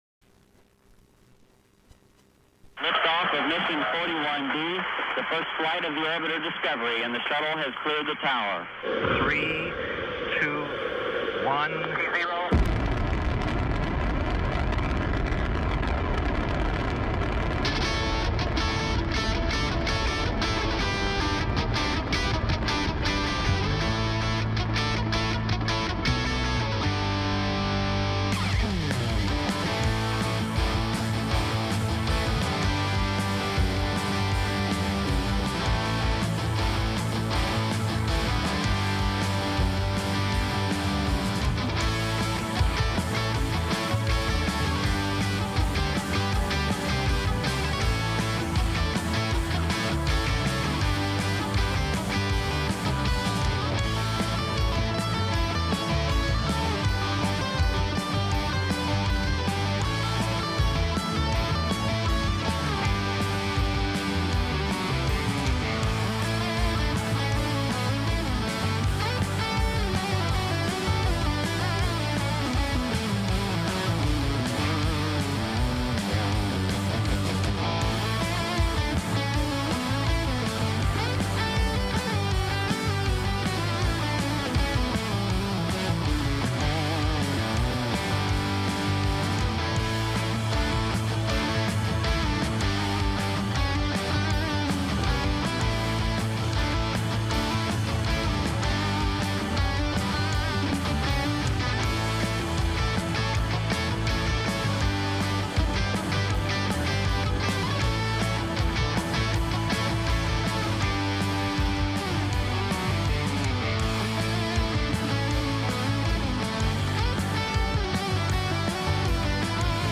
Programa con la mejor musica rock